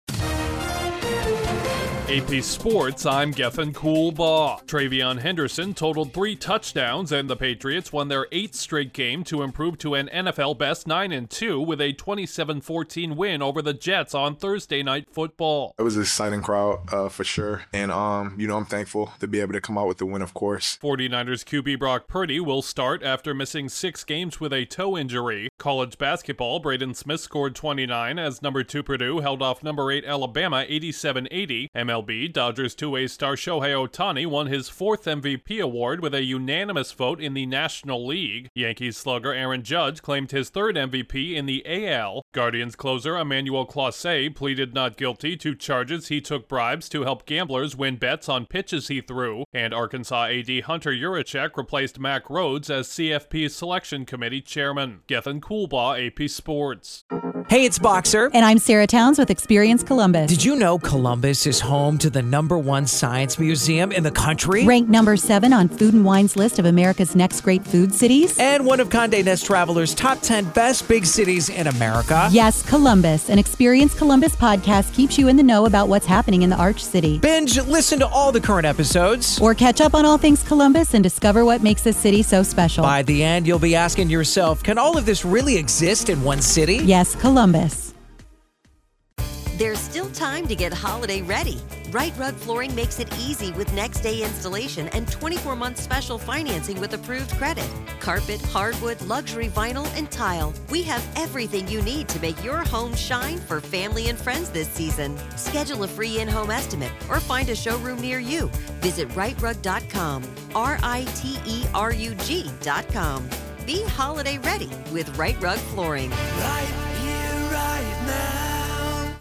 New England wins its NFL-best eighth straight on Thursday Night Football, a Pro Bowl QB returns in the NFC West, the No. 2 ranked team avoids an upset in college basketball, Dodgers and Yankees stars claim baseball’s MVP awards, an MLB pitcher pleads not guilty to pitch rigging and a change atop the CFP selection committee. Correspondent